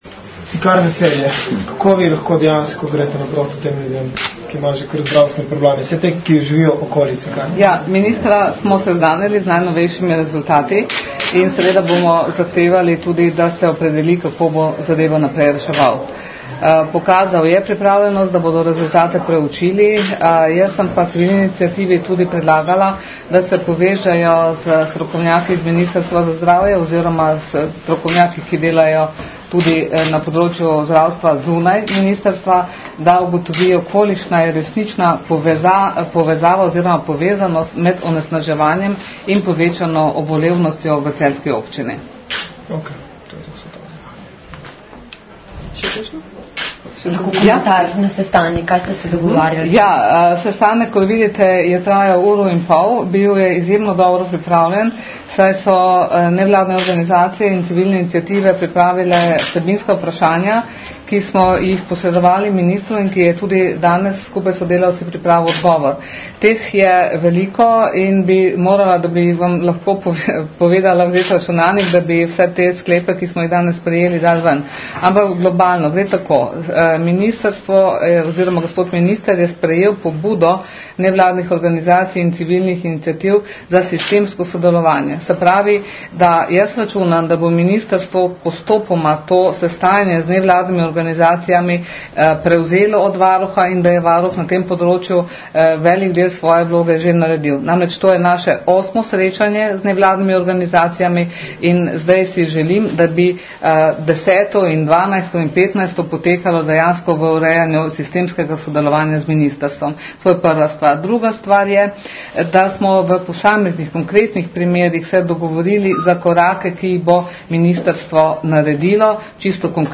Zvočni posnetek novinarske konference (MP3)
Srecanje_NVO_okolje_in_Zarnic_-_izjava.mp3